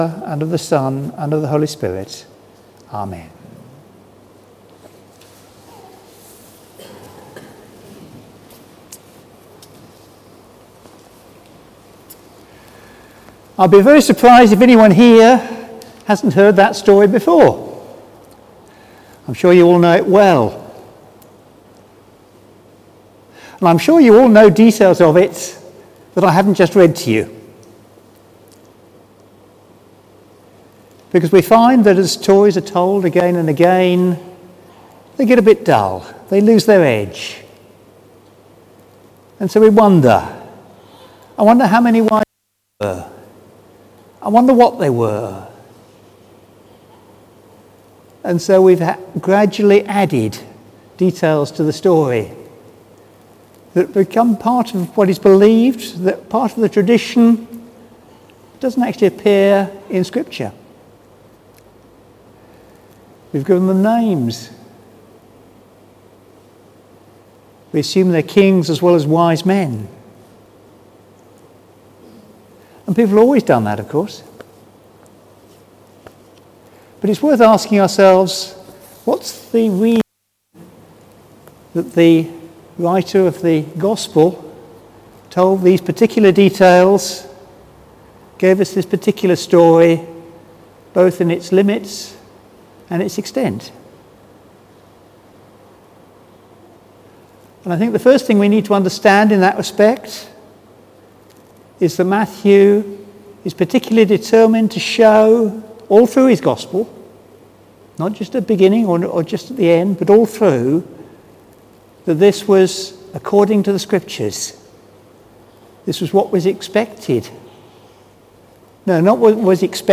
Sermon: God With Us | St Paul + St Stephen Gloucester